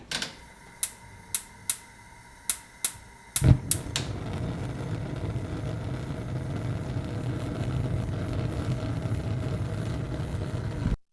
mutfak ses efektleri:)